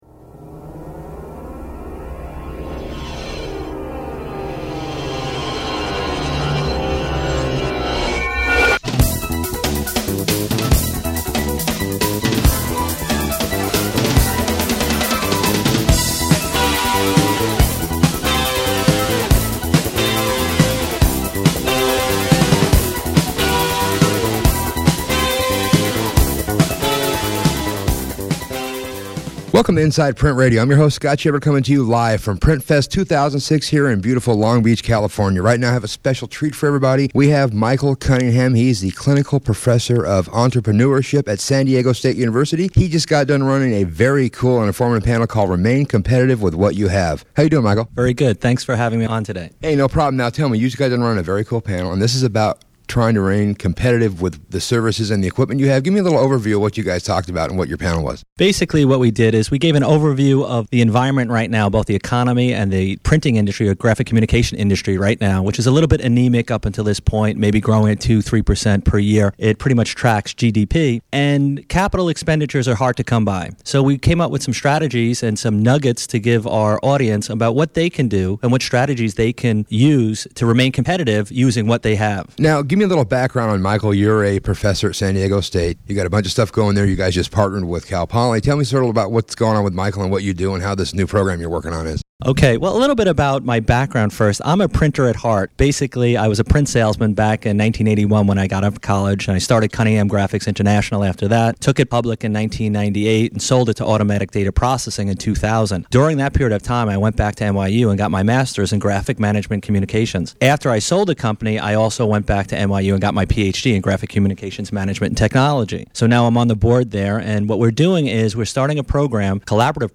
Inside Print Radio Interview